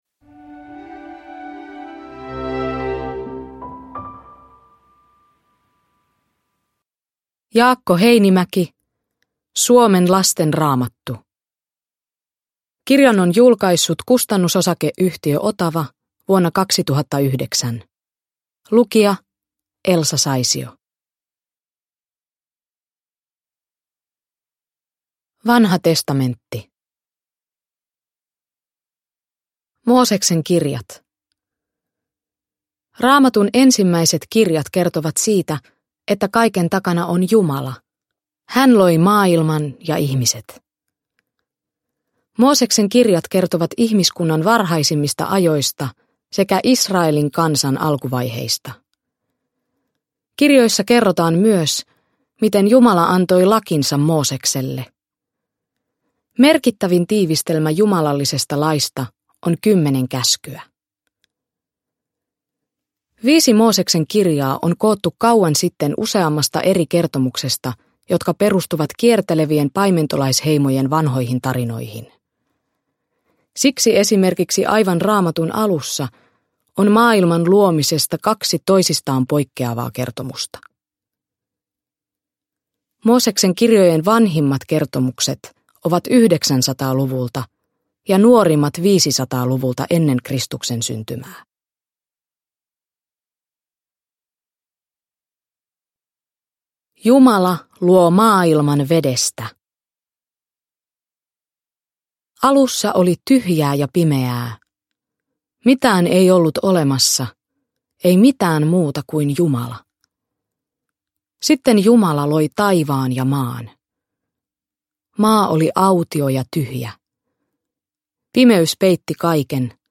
Suomen lasten Raamattu – Ljudbok
Uppläsare: Elsa Saisio